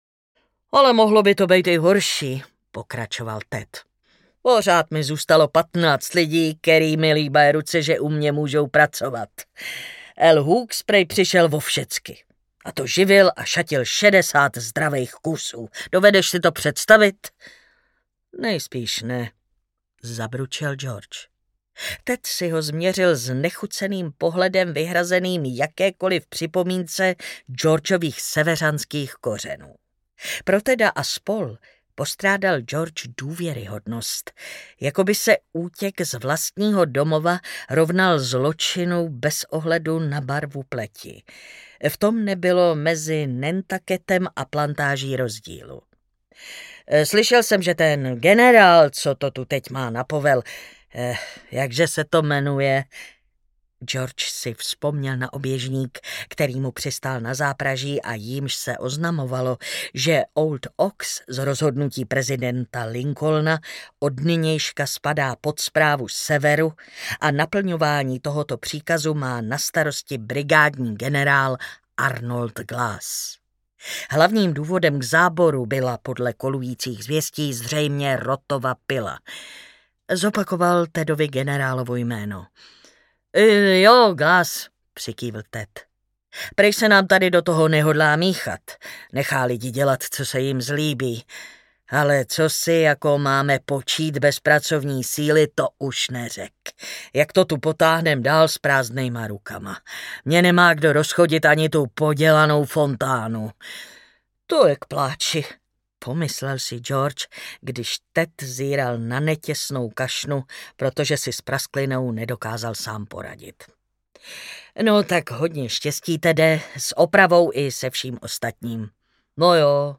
Svěžest vody audiokniha
Ukázka z knihy
Čte Martina Hudečková.